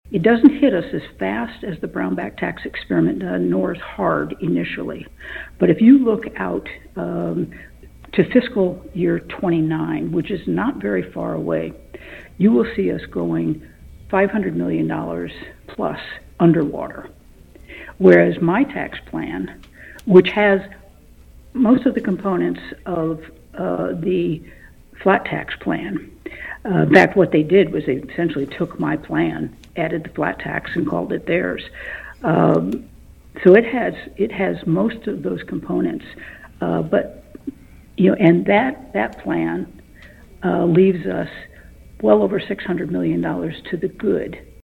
Kansas Governor Laura Kelly discussed several key topics in the Statehouse during an interview airing Wednesday on KVOE’s Newsmaker segment.